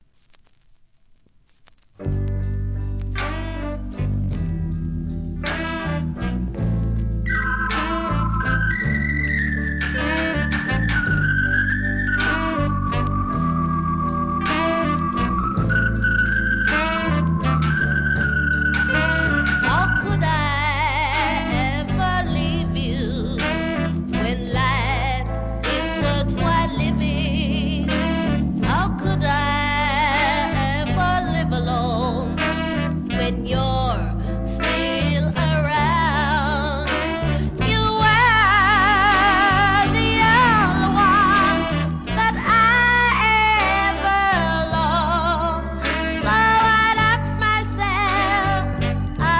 REGGAE
NICE VOCAL SKA!